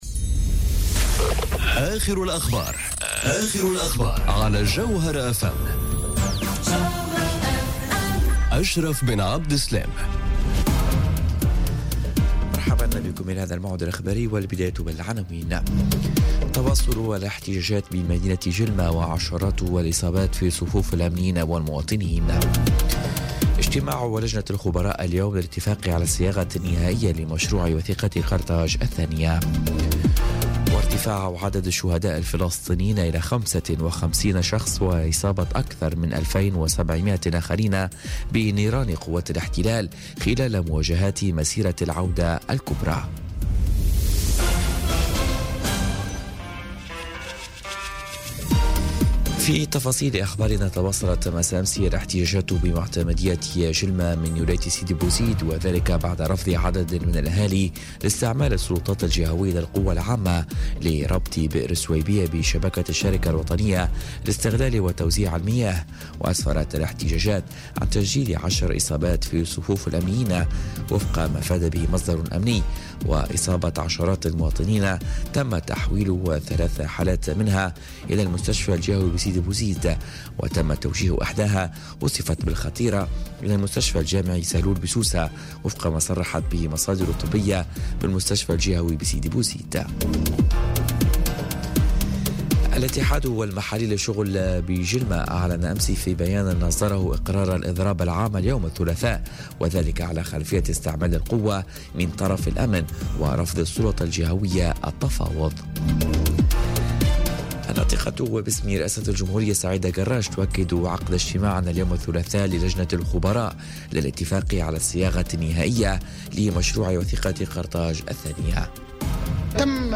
نشرة أخبار منتصف الليل ليوم الثلاثاء 15 ماي 2018